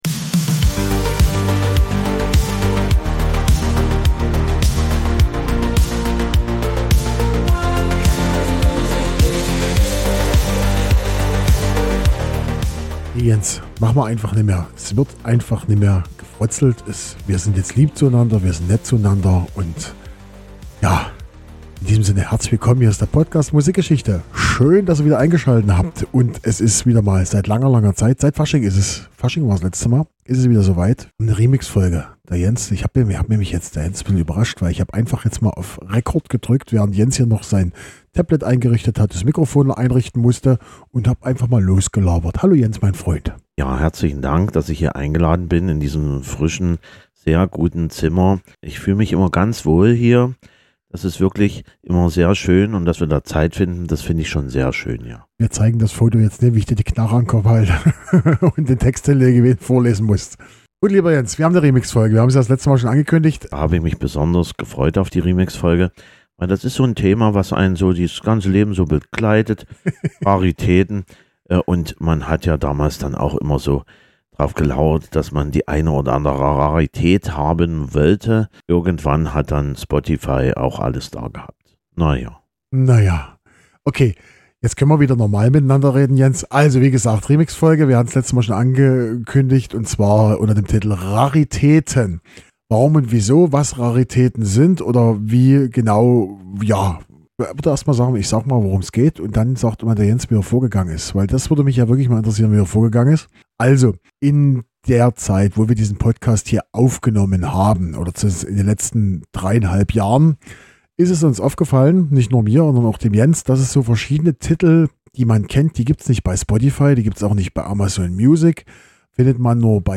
Links KI-Song zu dieser Folge Credits Podcastintro/-outro by Suno Hosted on Acast.